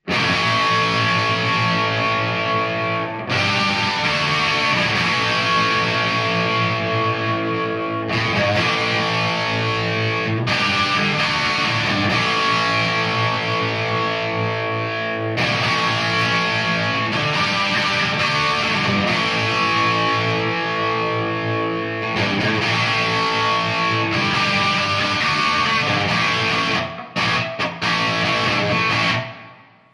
Le tout enregistré avec la même guitare, le même micro positionné au même endroit avec le même baffle (Rivera monté en Scumbacks).
La guitare est une vieille Les Paul Junior de '59, montée en P90 donc.
Marshall JVM 205H
La JVM a un son plus proche des Master Volume des 70s, par rapport à la Superlead.
Sur ces samples je trouve quand-même que la JVM sonne bien maigre par rapport à la superlead
Les Paul Jr et Marshall 205H.mp3